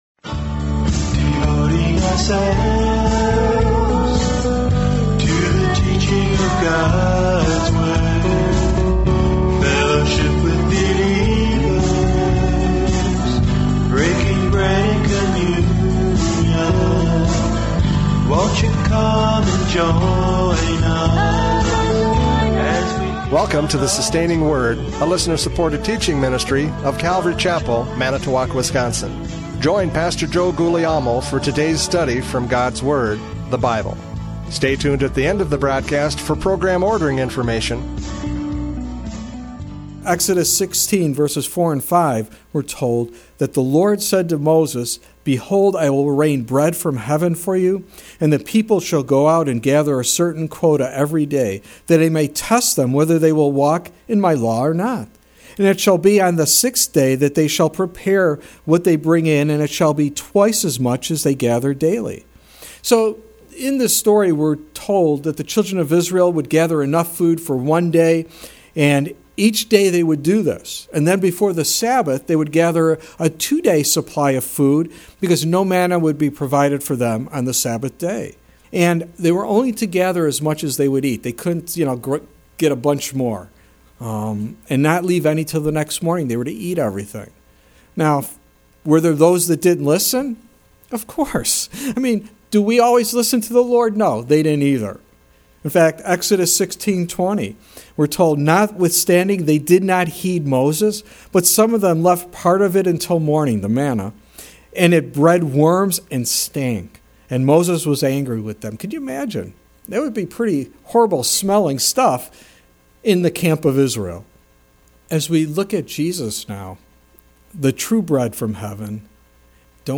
John 6:30-40 Service Type: Radio Programs « John 6:30-40 The Bread of Life!